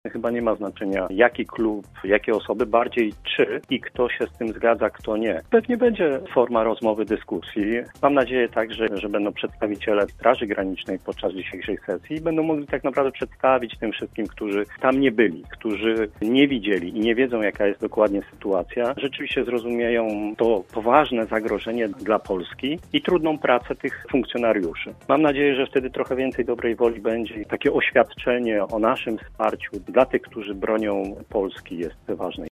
Piotr Barczak, przewodniczący Rady Miasta z klubu PiS, liczy na przyjęcie treści oświadczenia: